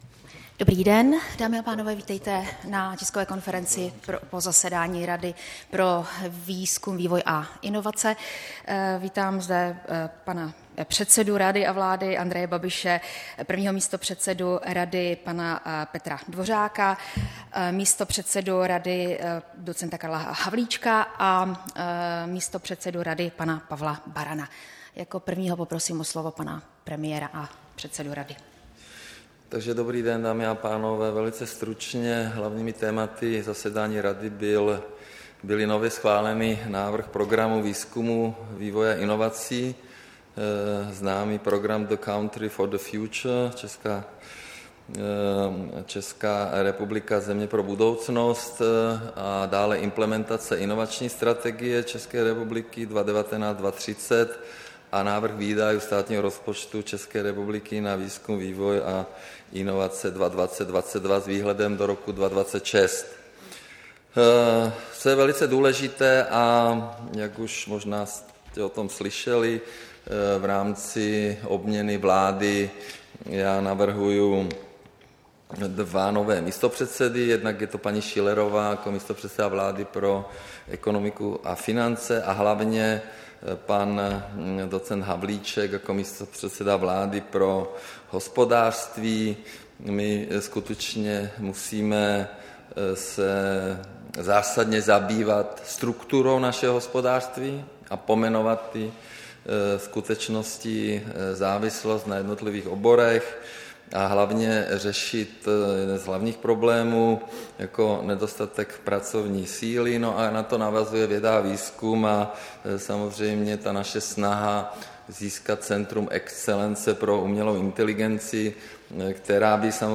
Tisková konference po jednání Rady pro výzkum, vývoj a inovace, 26. dubna 2019